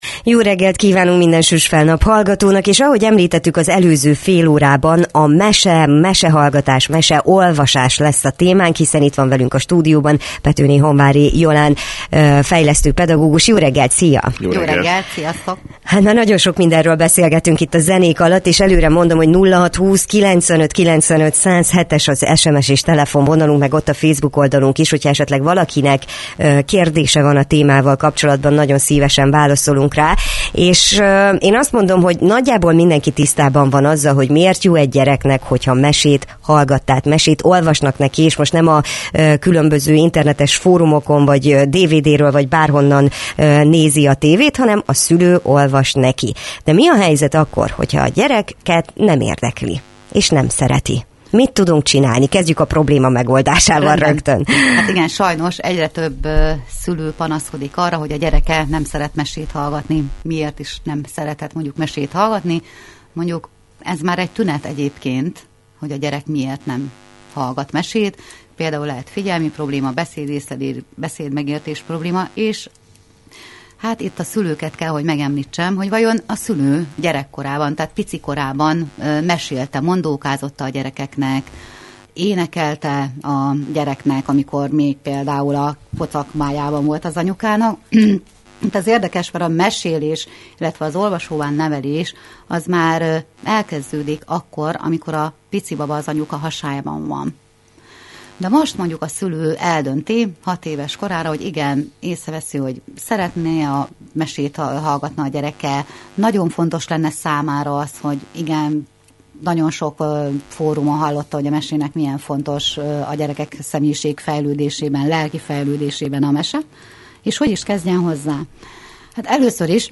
Hogyan szerettessük meg a mesehallgatást és miért olyan fontos a mindennapi mesélés a gyerekeknek? Erről beszélgettünk a Lakihegy Rádióban.